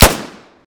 sounds / weapons / thompson